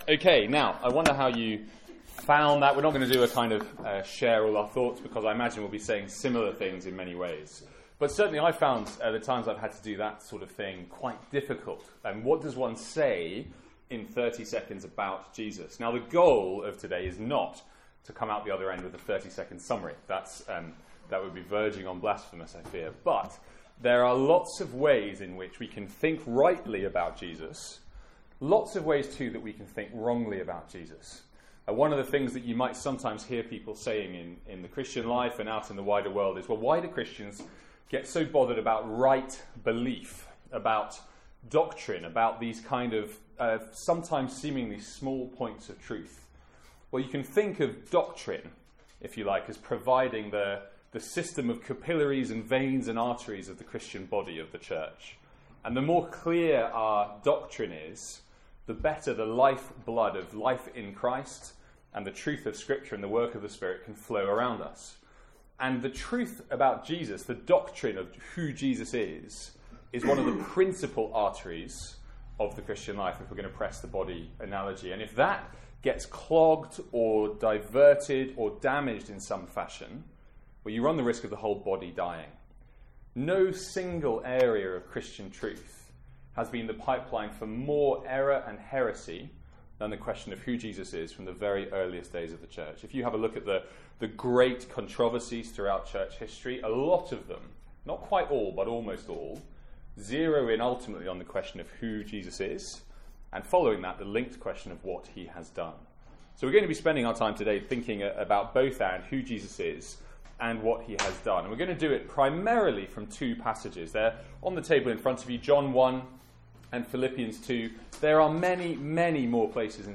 From the Student Lunch on 2nd October 2016.